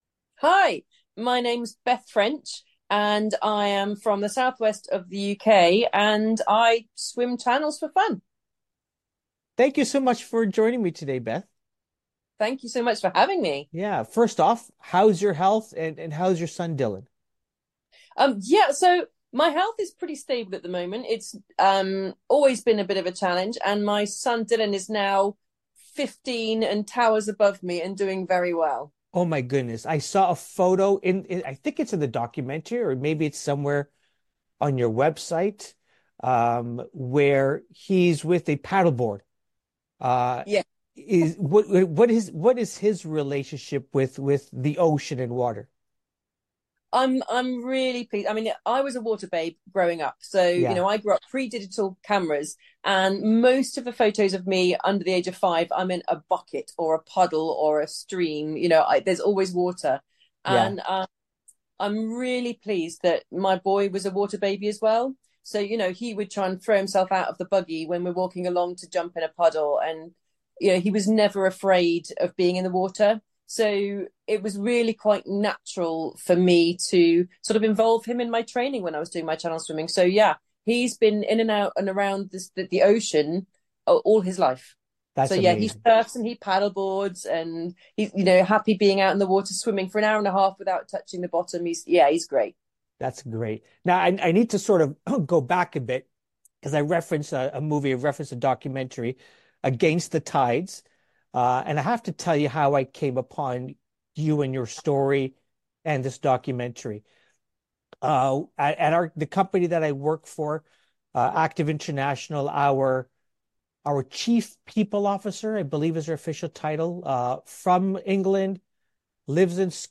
Welcome is a series of in depth conversations with interesting people doing interesting things.
Interesting conversations with interesting people.